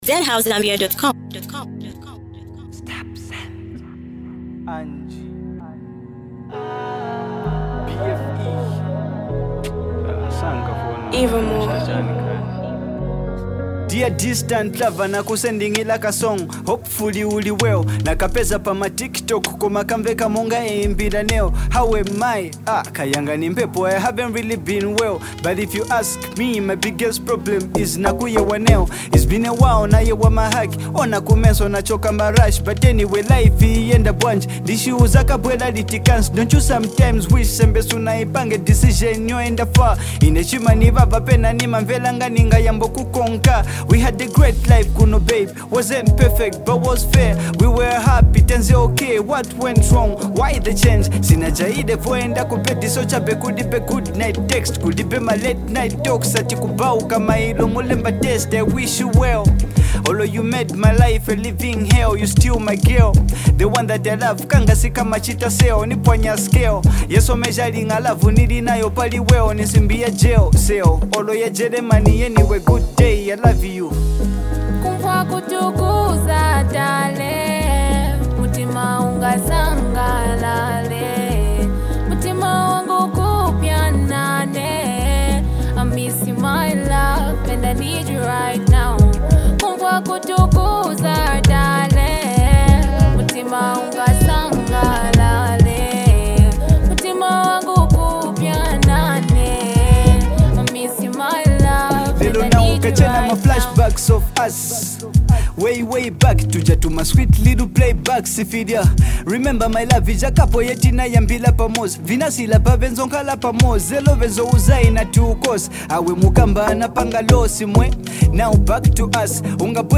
This emotional track is a love letter to someone far away